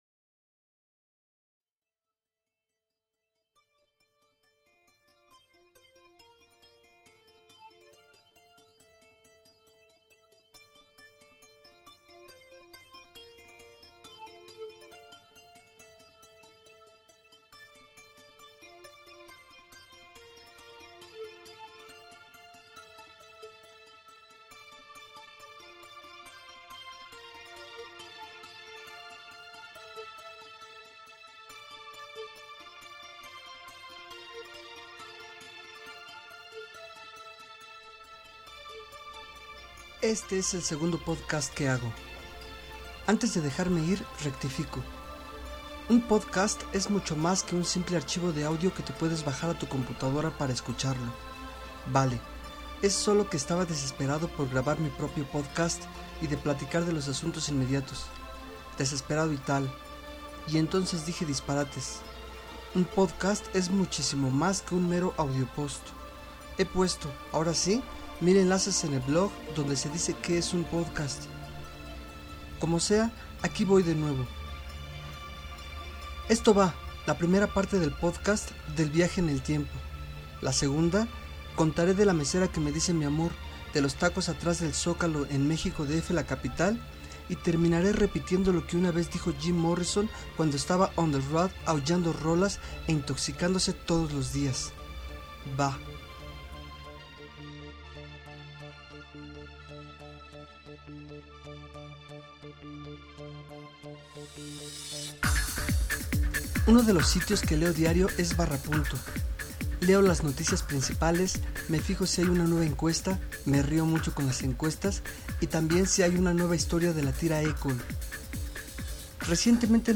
Se escuchan mas nítidos: